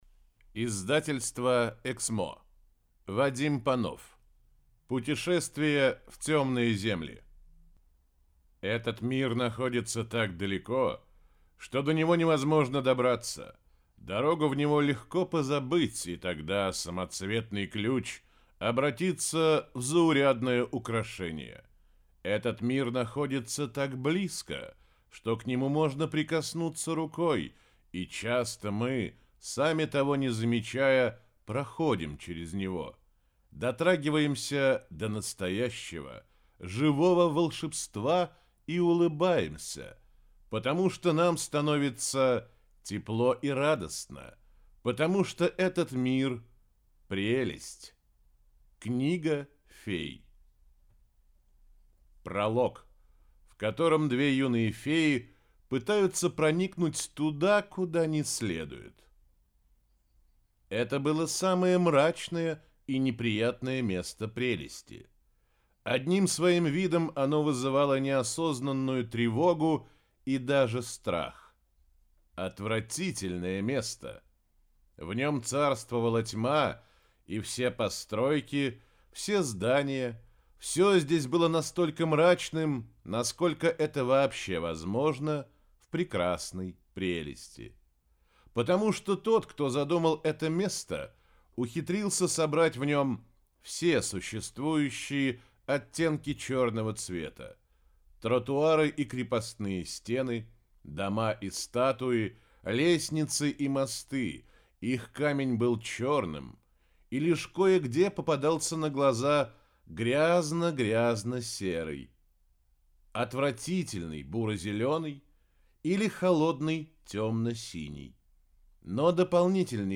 Аудиокнига Путешествие в Тёмные Земли - купить, скачать и слушать онлайн | КнигоПоиск